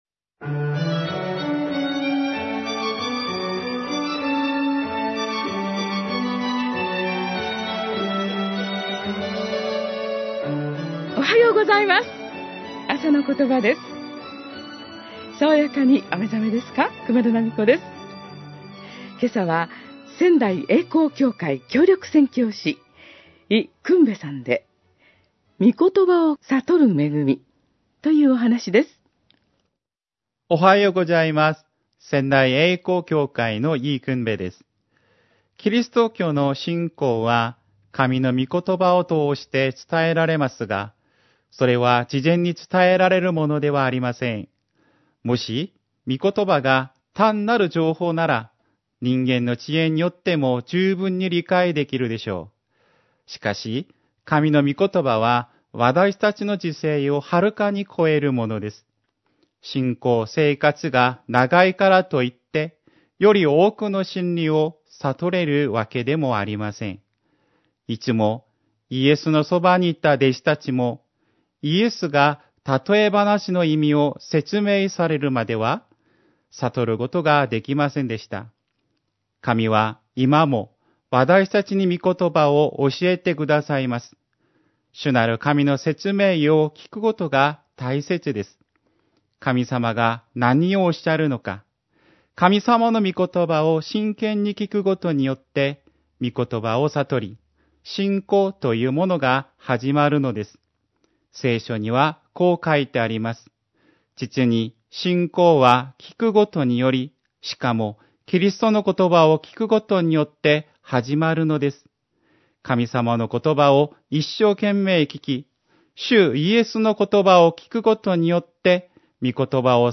メッセージ： み言葉を悟る恵み